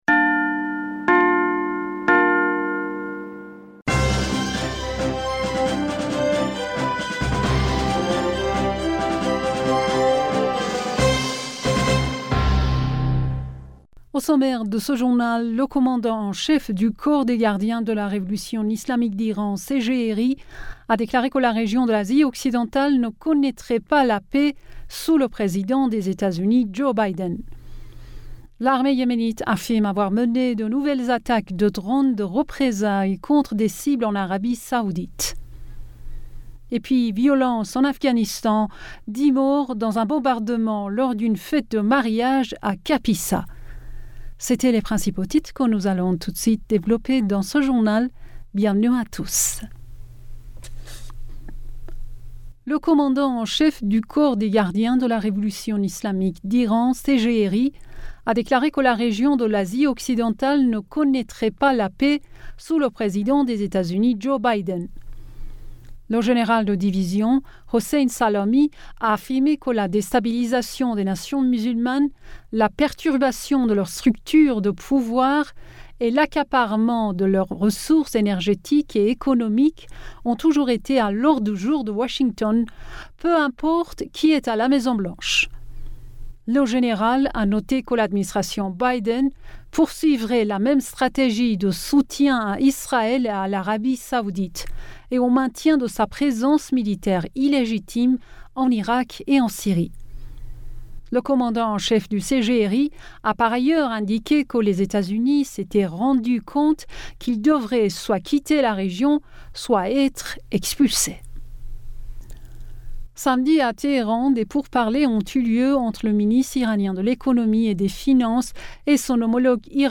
Bulletin d'information du 30 mai 2021